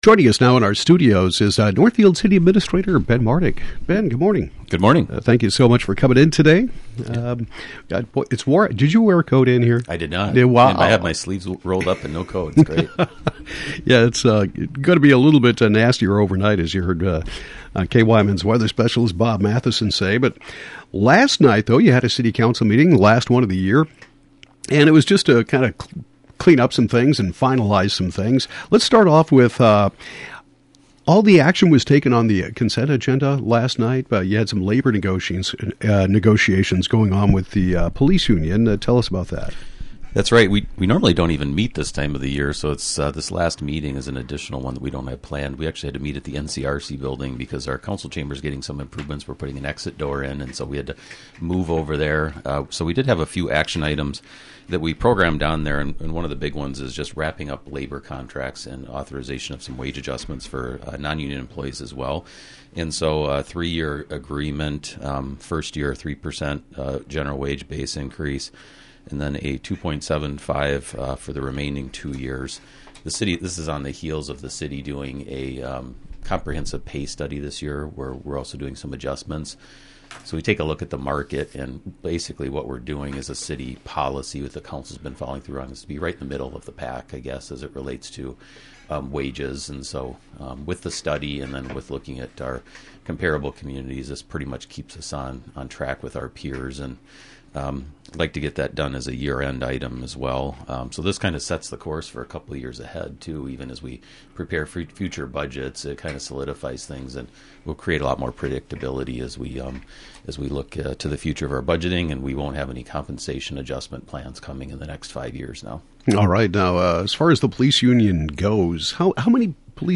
Northfield City Administrator Ben Martig discusses City Council meeting